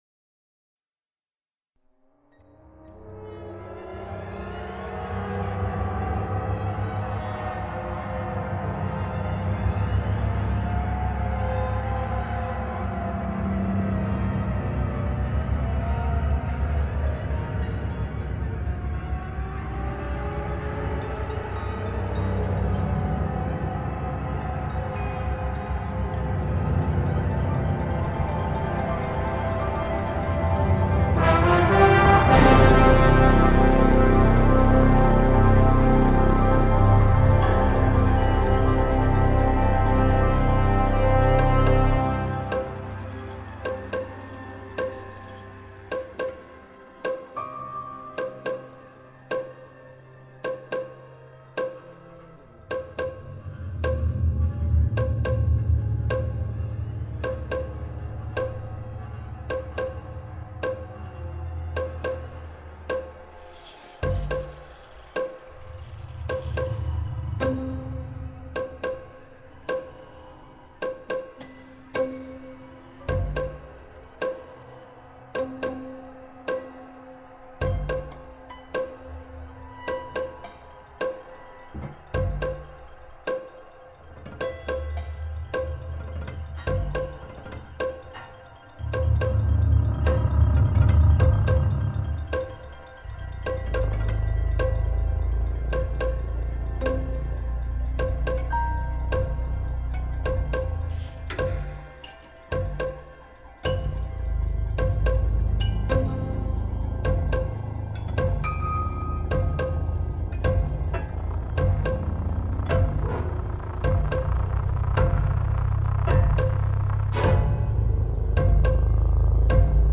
Soundtrack, Classical, Jazz, Synth-Pop